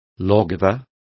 Complete with pronunciation of the translation of lawgiver.